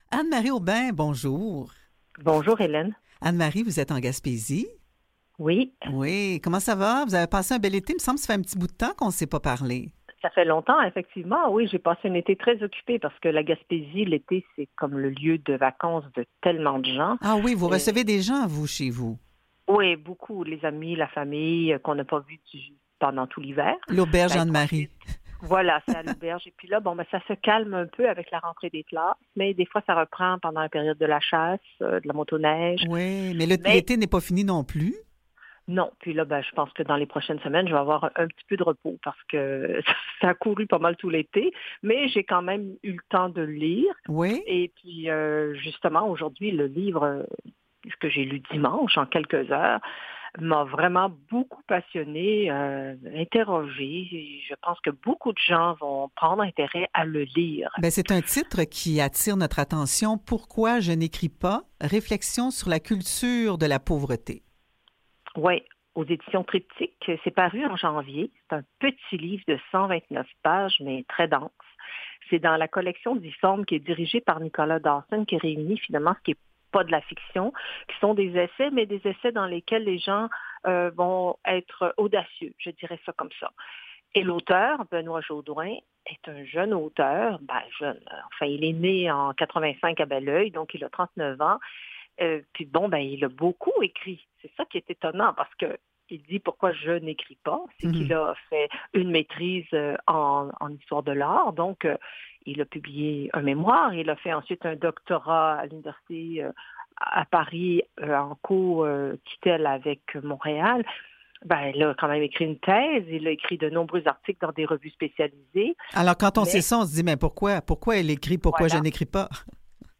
chroniqueuse